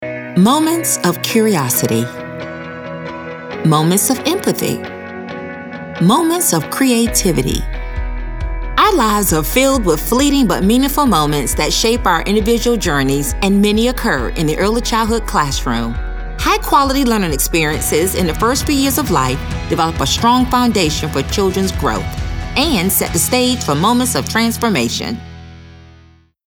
African American, caring, confident, conversational, genuine, informative, mature, motivational, smooth, thoughtful, warm